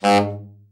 TENOR SN   1.wav